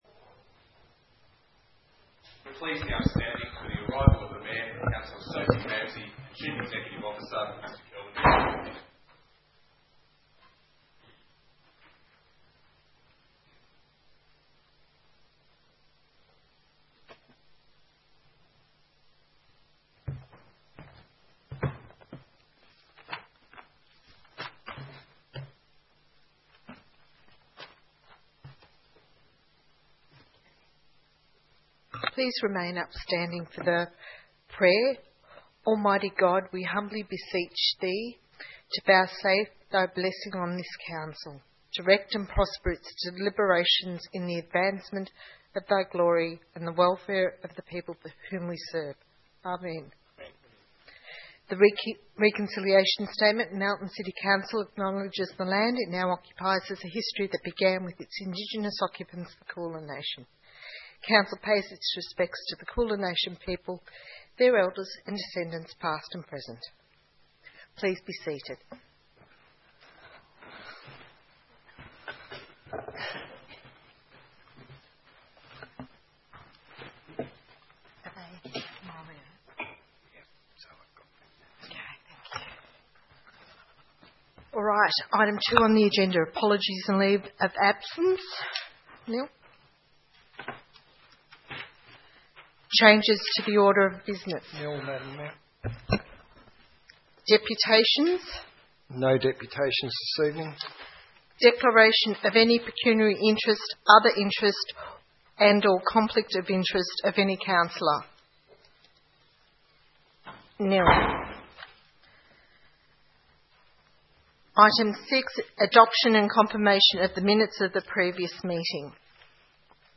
21 April 2015 - Ordinary Council Meeting